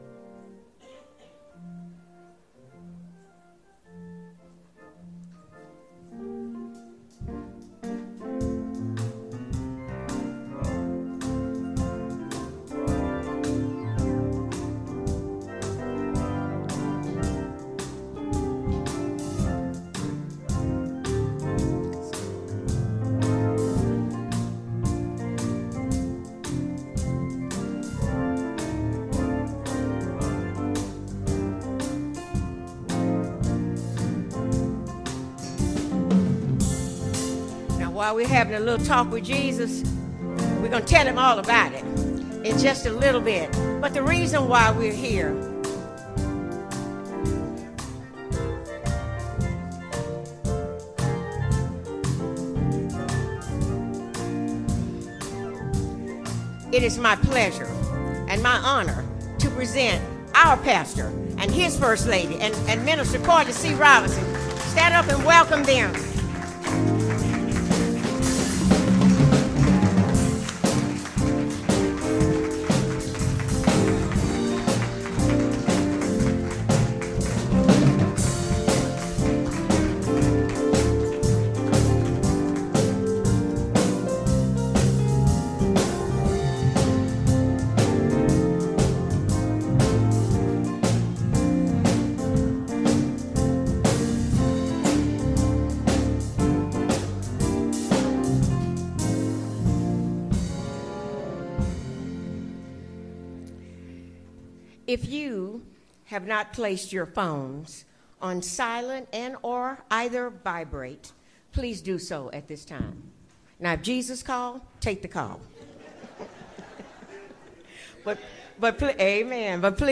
Pastor's Installation Celebration -